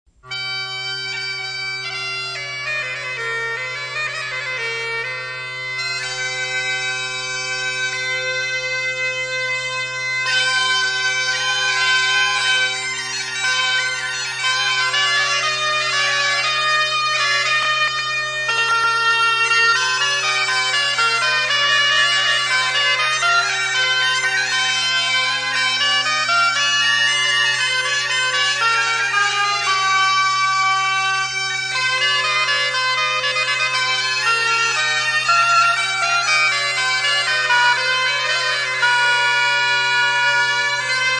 CORNEMUSE
Clemencic Consort